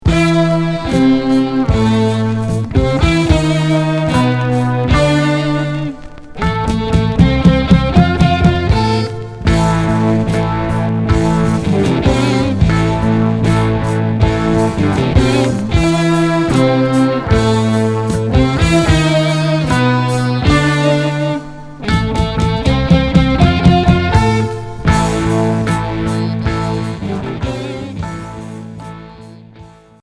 Progressif Unique 45t